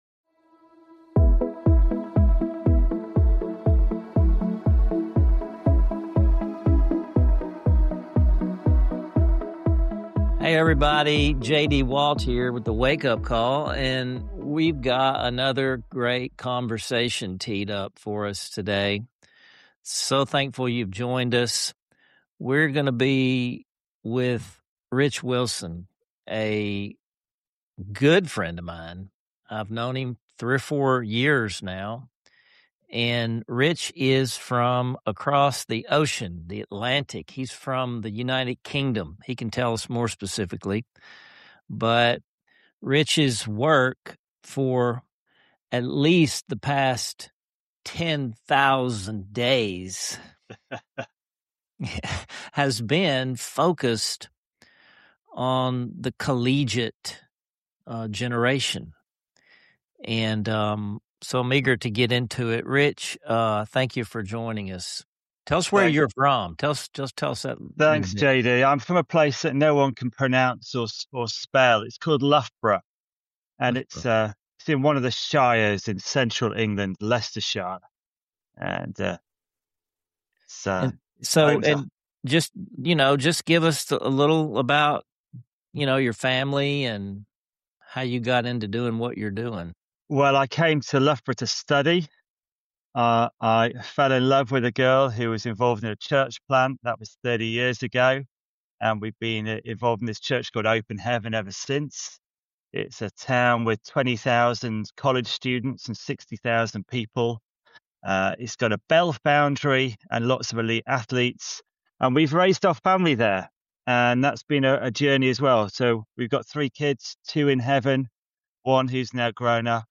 Wake-Up Call Conversations